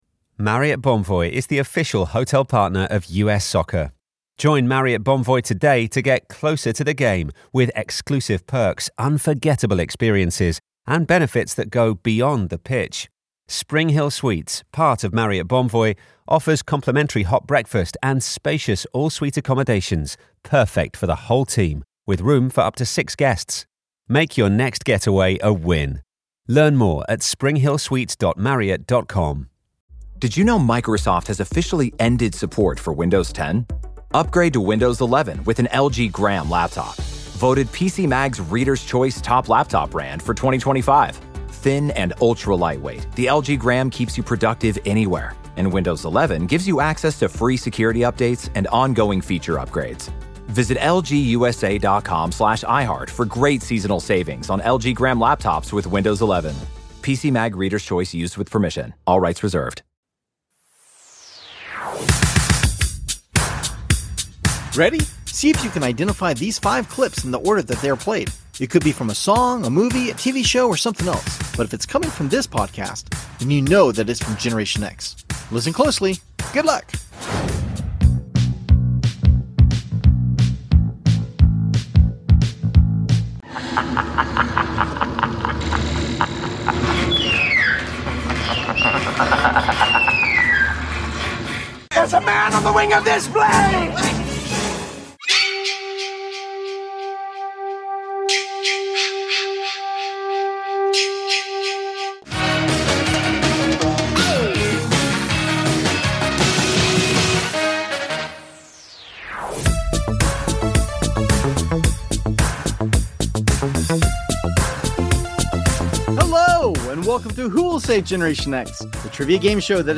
In this episode our returning guests bring plenty of laughs as we play games and reminisce about growing up during Generation X during Halloween.